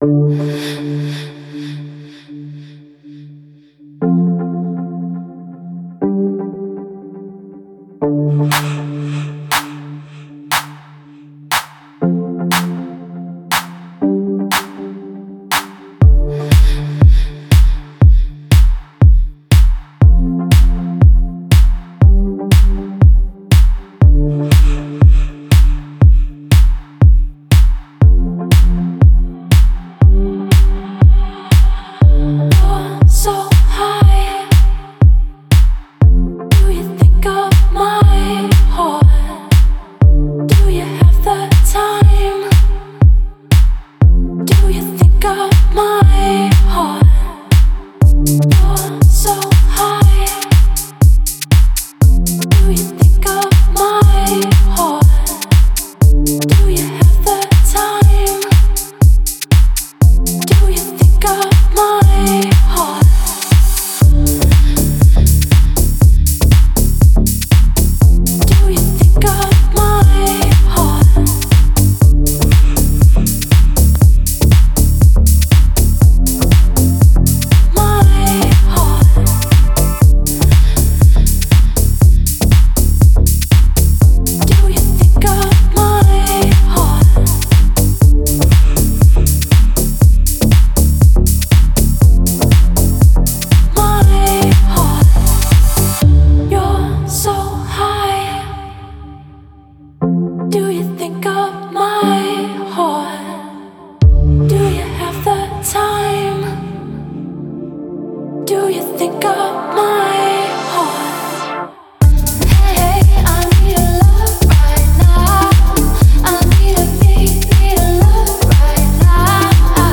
Очень крутые девочки и продюсеры электронной музыки.